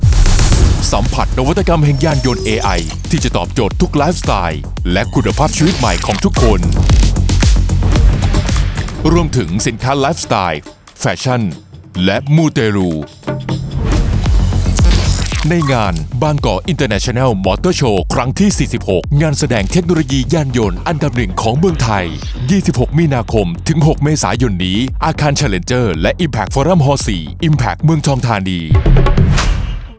Radio Spot Motorshow46 30วิ